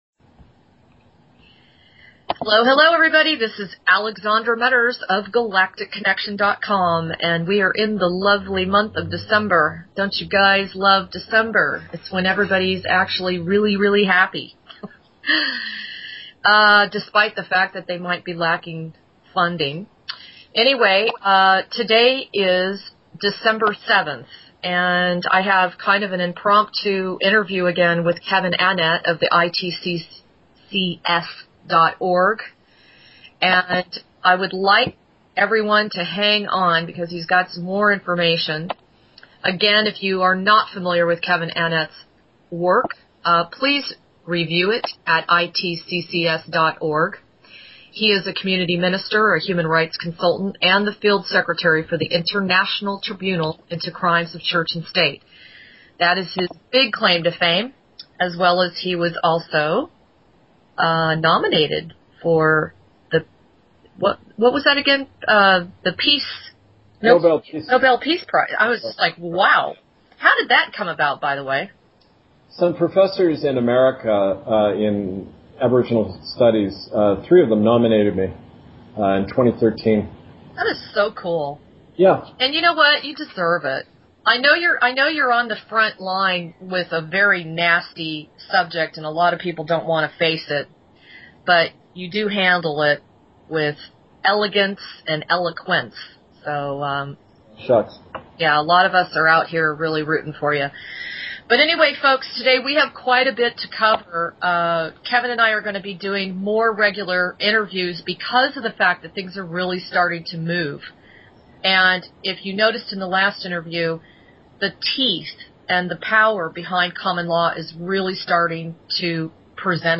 Talk Show Episode, Audio Podcast, Galactic Connection: The Wave Is Growing!